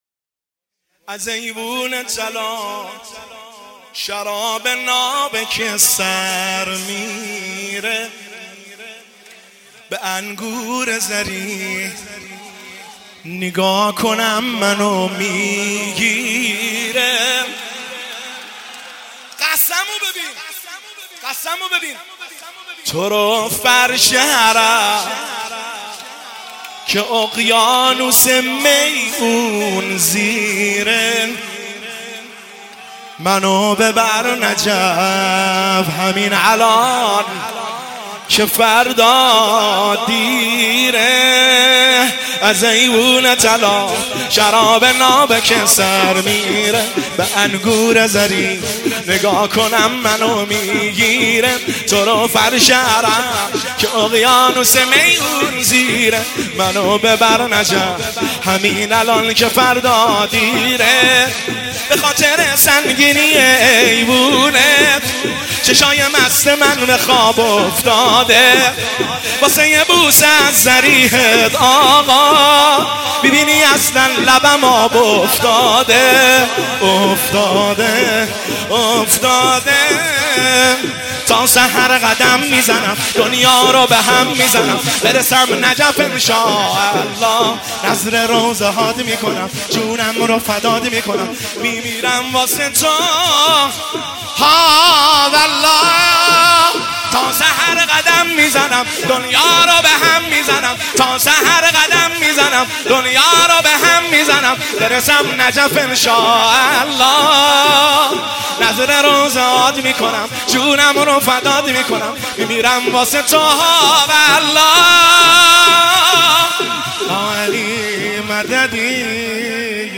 شور شب 20 رمضان المبارک 1403